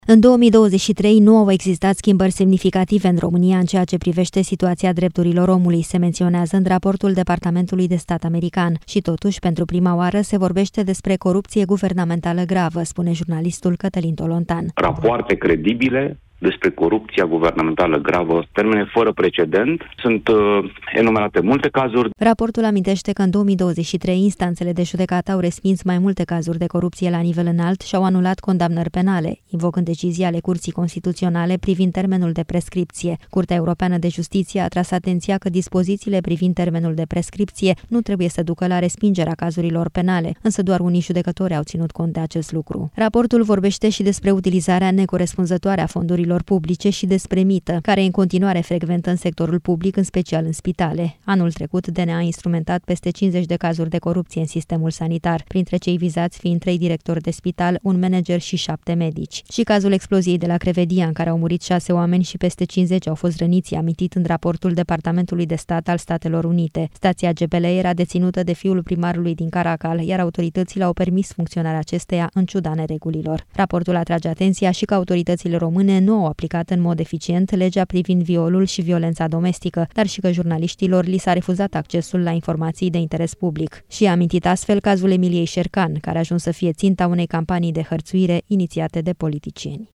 „Rapoarte credibile despre corupția guvernamentală gravă, termene fără precedent. Sunt enumerate multe cazuri”, a spus jurnalistul Cătălin Tolontan în emisiunea „Deșpteptarea” la Europa FM.